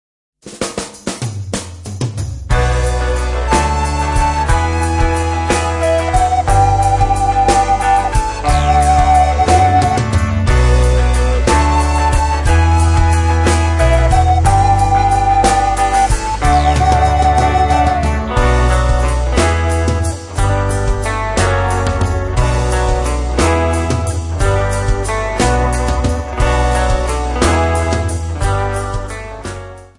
Jolly contemporary religious Songs sung by Children.